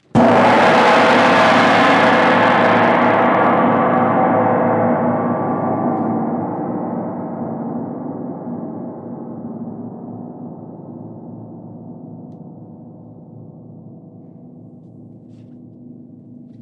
Grandgong_2eme_essaie.wav